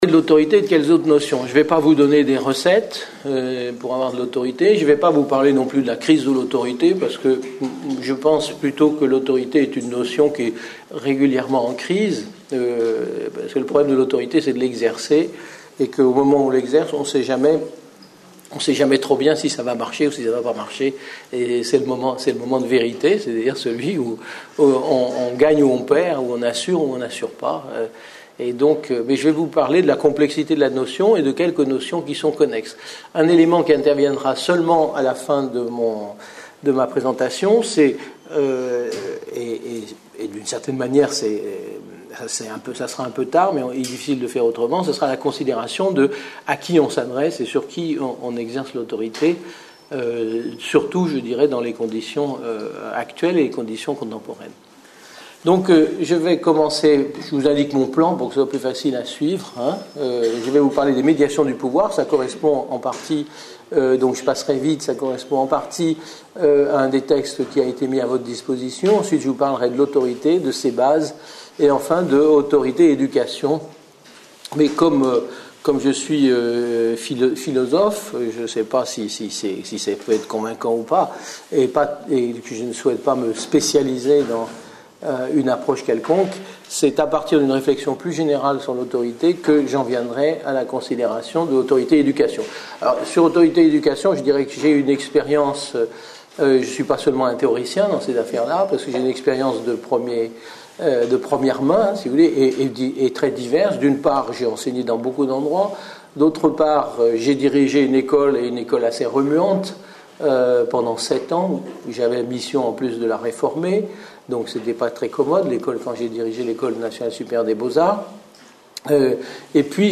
Une conférence de l'UTLS au lycée Par Yves Michaud, philosophe